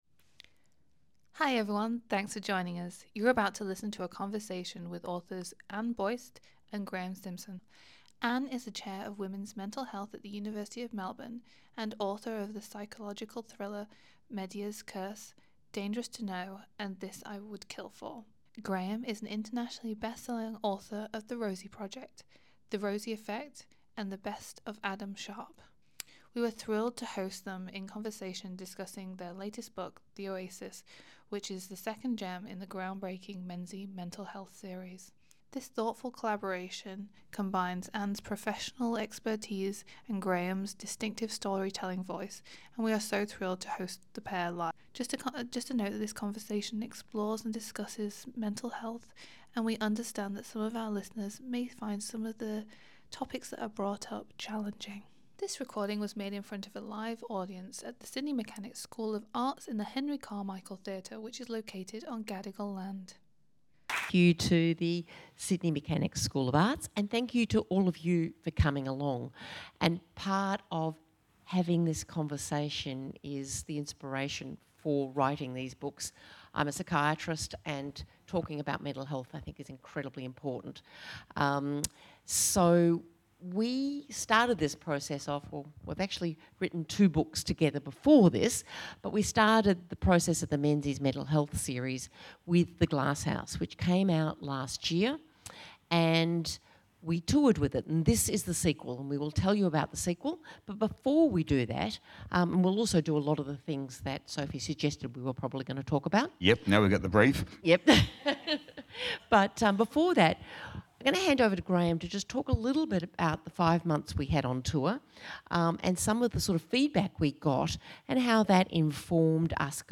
Listen Again: Anne Buist and Graeme Simsion Author Talk
We were thrilled to host authors Anne Buist and Graeme Simsion launch of The Oasis, the second gem in the groundbreaking Menzies Mental Health series!